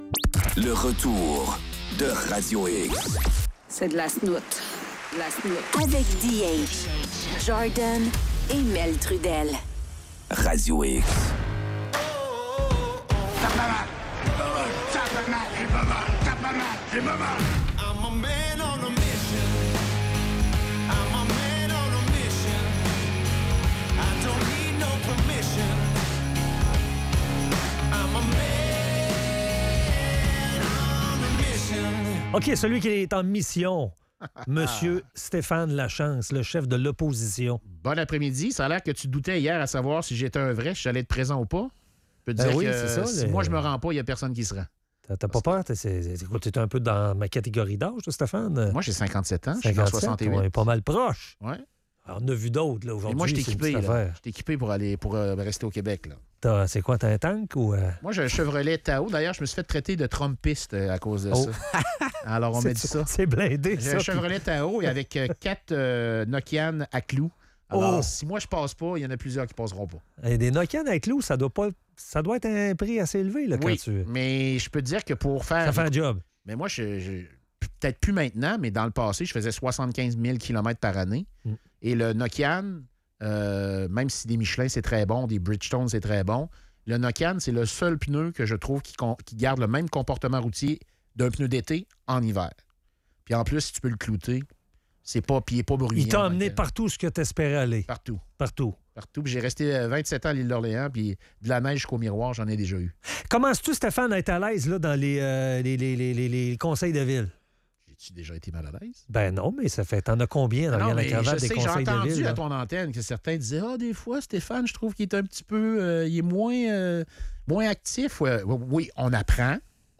La chronique de Stéphane Lachance, chef de l'opposition.